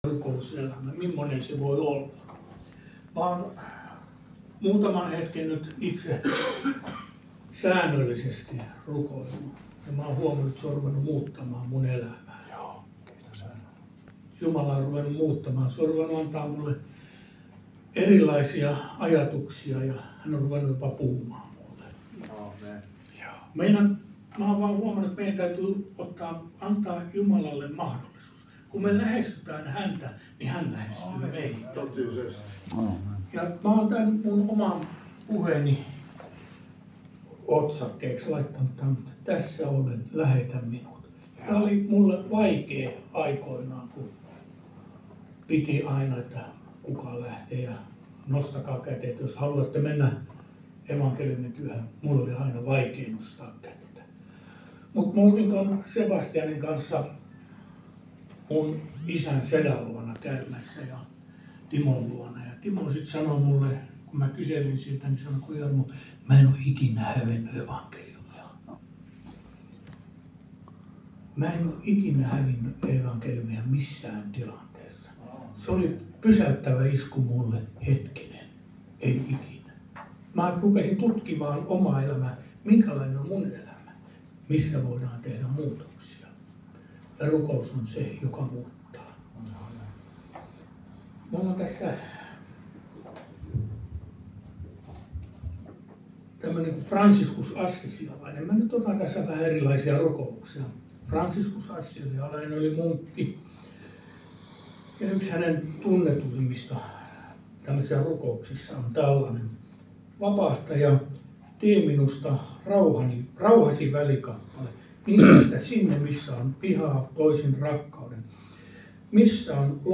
Service Type: Sanan ja rukouksen ilta